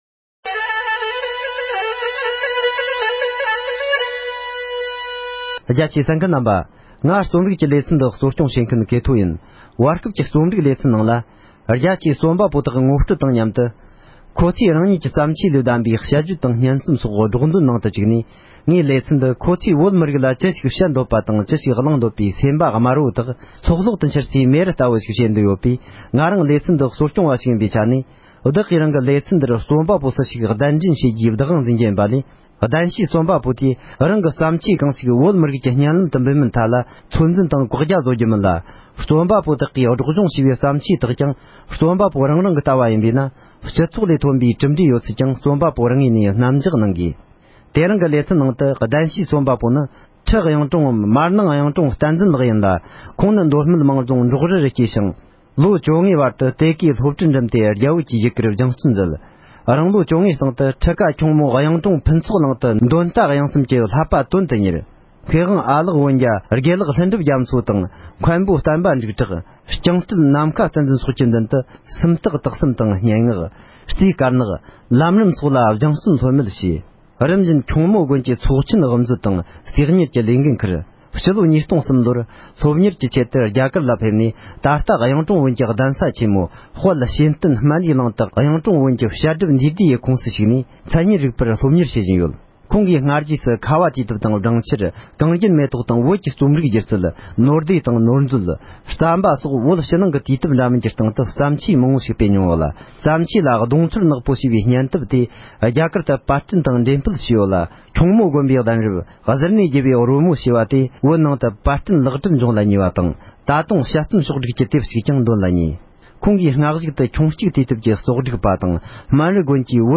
སྙན་ངག་པ་ཞེས་པའི་སྙན་རྩོམ་དེ་གྱེར་འདོན་ཞུས་པ་ཞིག་ལ་གསན་རོགས་གནོངས༎